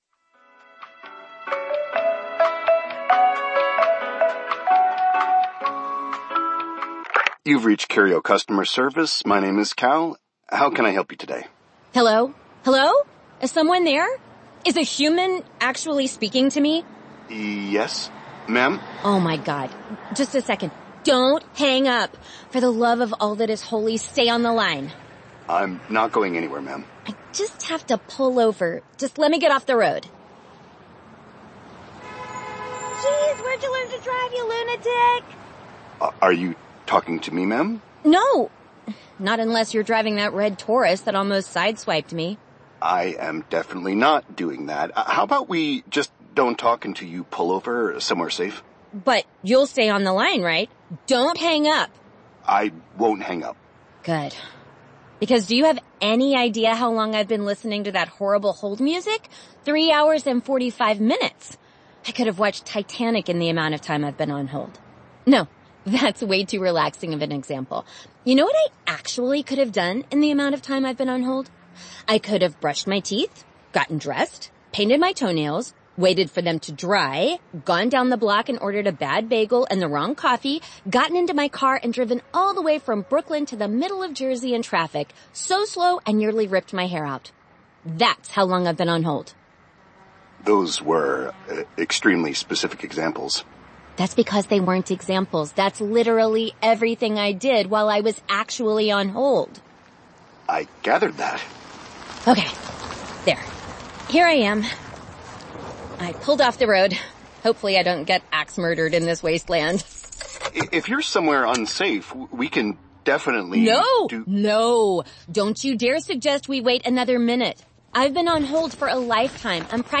Featured Releasesaudiobooks